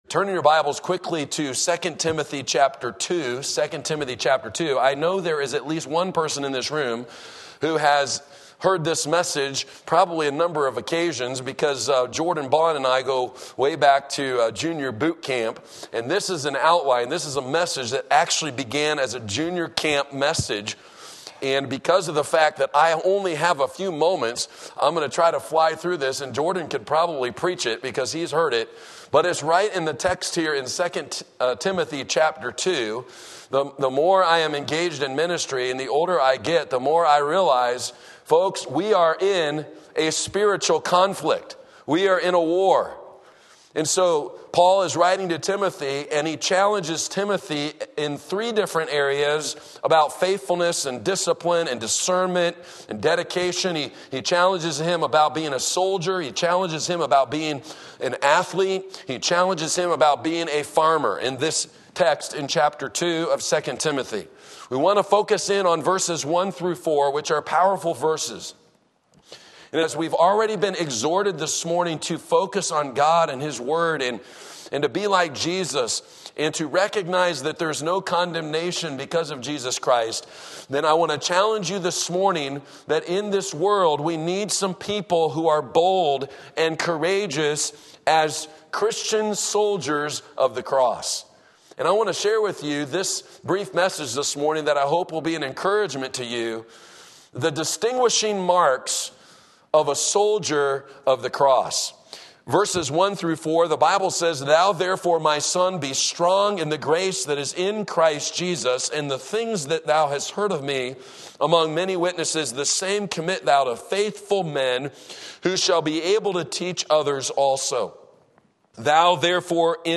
Sermon Link
The Marks of a Christian Soldier 2 TImothy 2:1-4 Sunday Morning Service